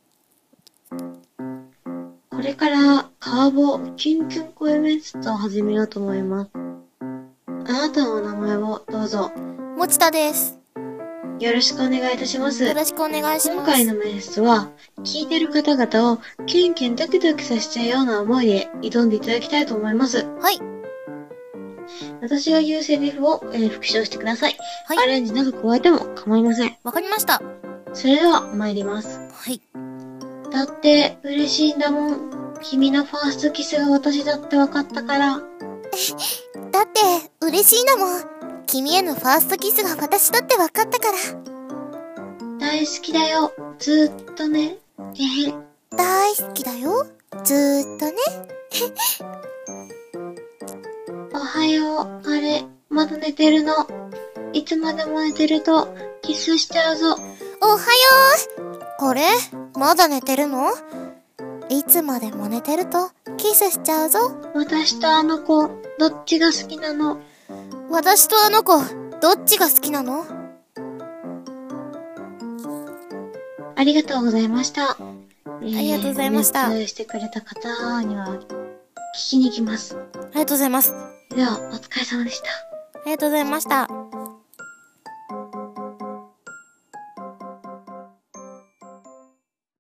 カワボきゅんきゅん声面接